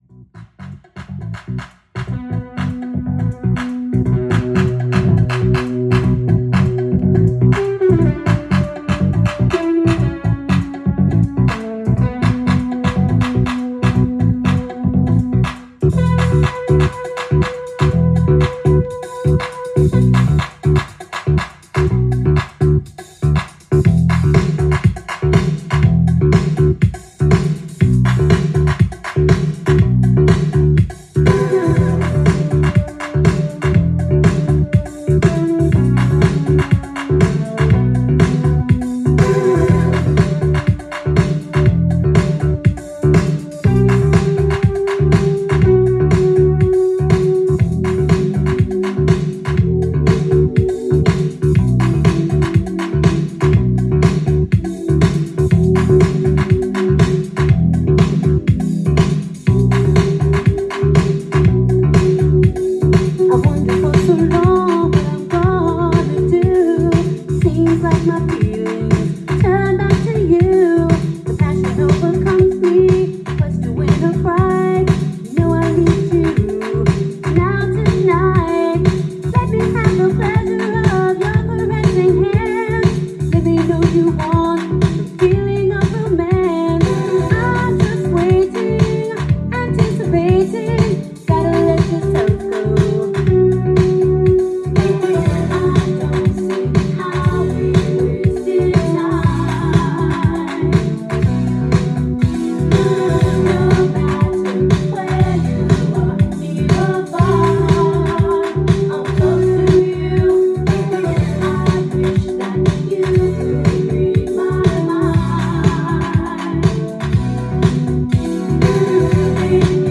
ジャンル：FUNK-DANCE SOUL
店頭で録音した音源の為、多少の外部音や音質の悪さはございますが、サンプルとしてご視聴ください。